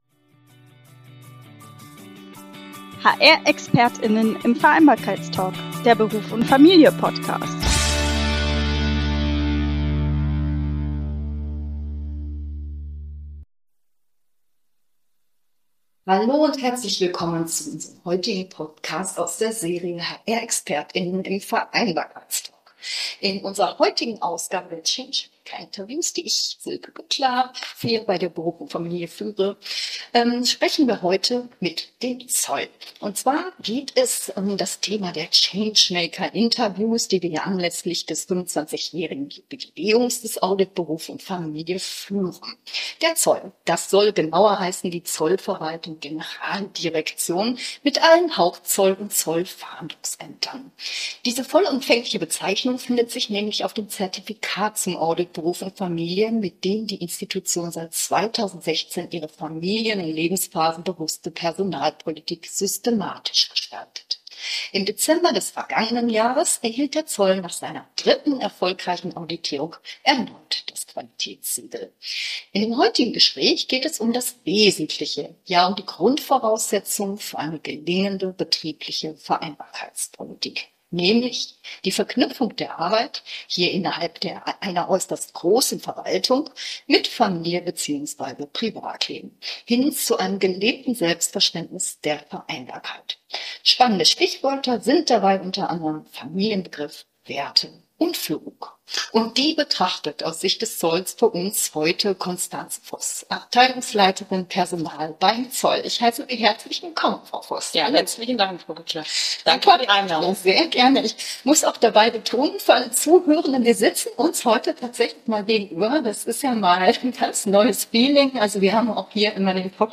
Beschreibung vor 2 Jahren Unser Partner in dieser Ausgabe der Change-Maker-Interviews, die wir anlässlich des 25-jährigen Jubiläums des audit berufundfamilie führen, ist der Zoll – genauer gesagt die Zollverwaltung – Generalzolldirektion (GZD) mit allen Hauptzoll- und Zollfahndungsämtern.